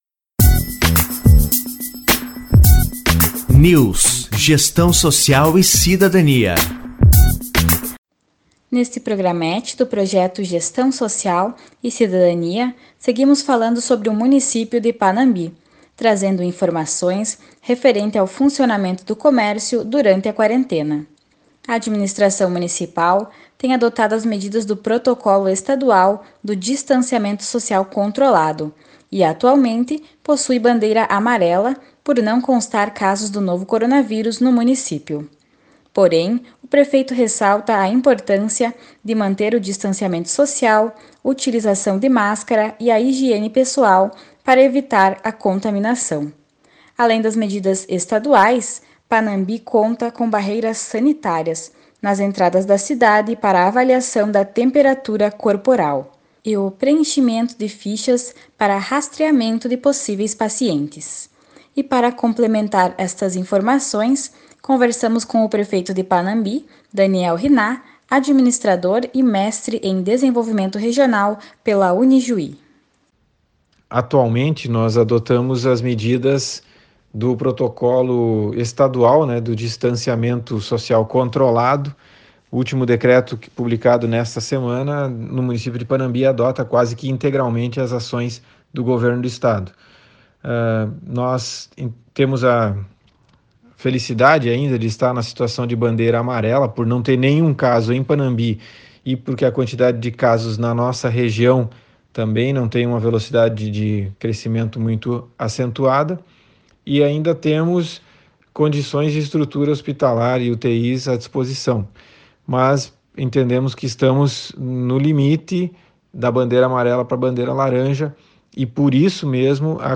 Entrevistado: Administrador, Mestre em Desenvolvimento Regional pela Unijuí e Prefeito de Panambi, Daniel Hinnah.